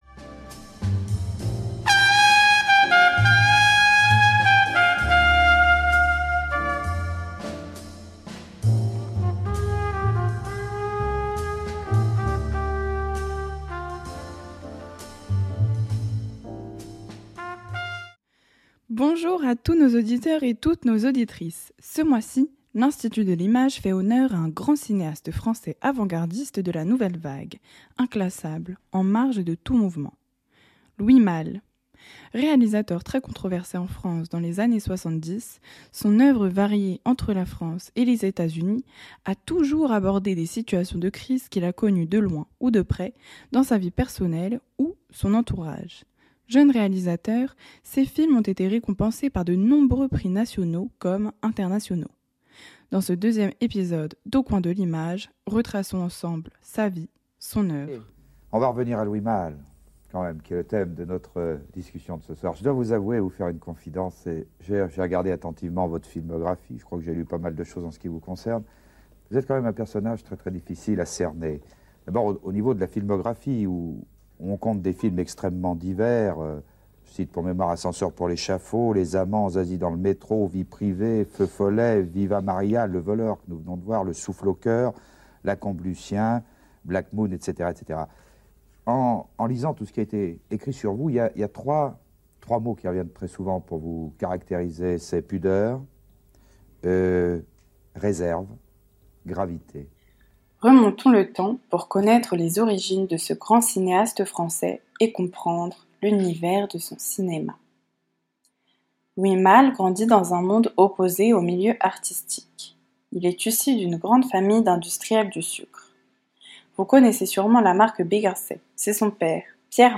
Entre biographie du cinéaste, musique, extraits de films, interviews et témoignages, laissez-vous portez par ce podcast qui vous fera tout (ou presque) connaître de Louis Malle !